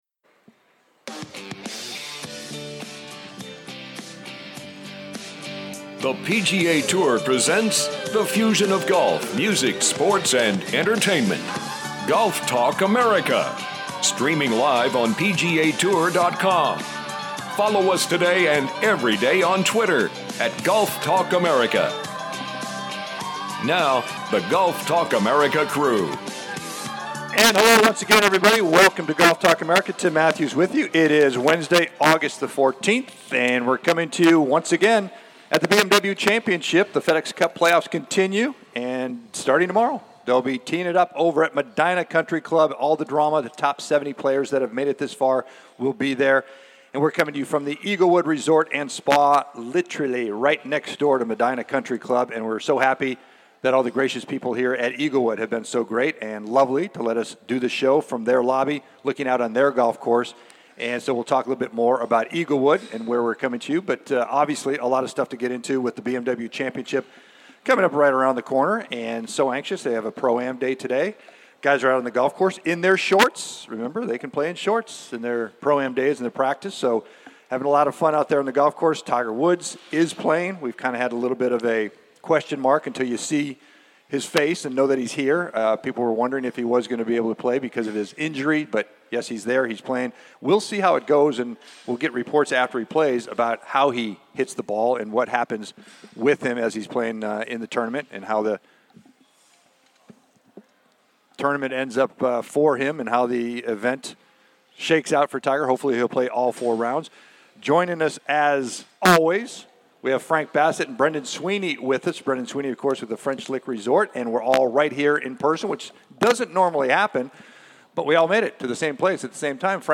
"LIVE" From The BMW Championship